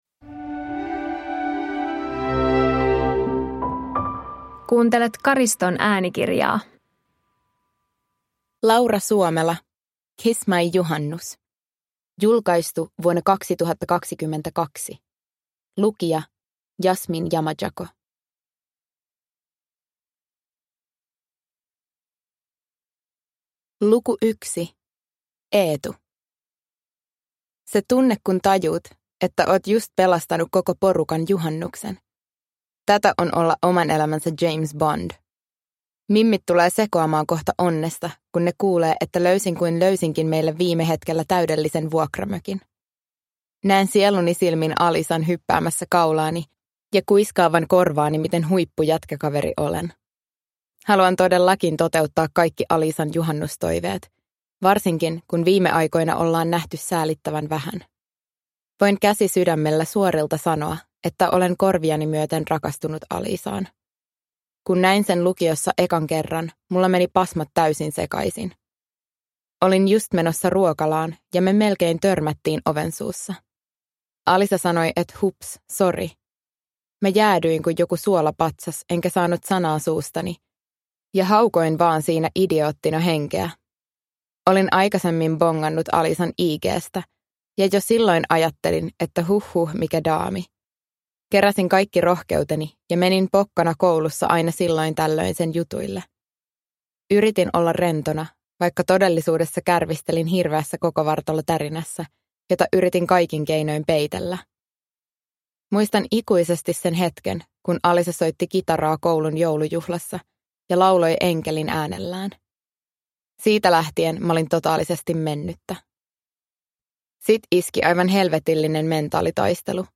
Kiss My JUHANNUS – Ljudbok – Laddas ner